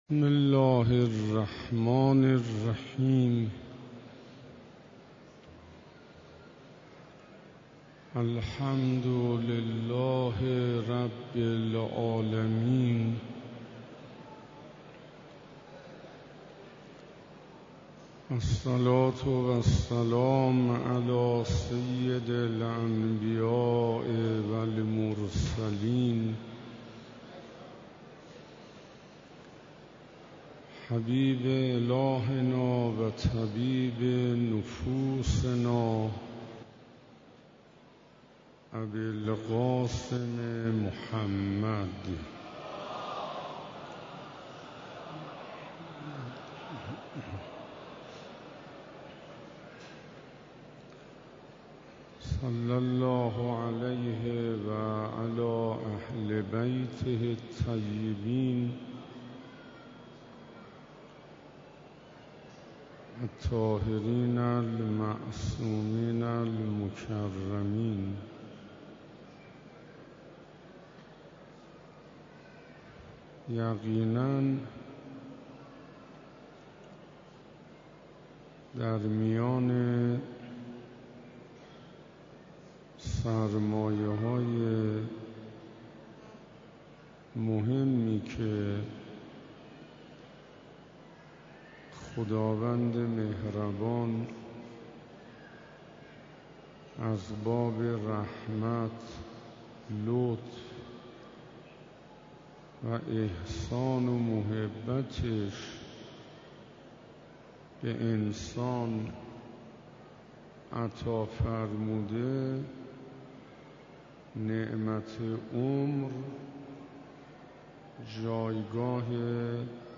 دهه دوم شعبان 97 - جلسه دهم - حرم مطهر حضرت فاطمه معصومه (س) - عمر